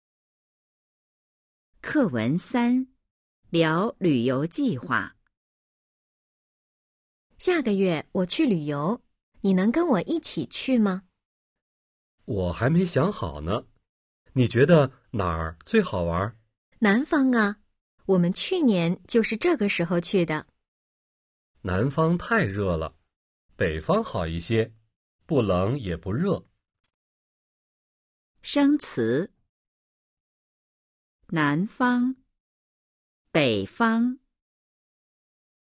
Bài hội thoại 3: 🔊 聊游戏计划 – Bàn về kế hoạch đi chơi  💿 01-03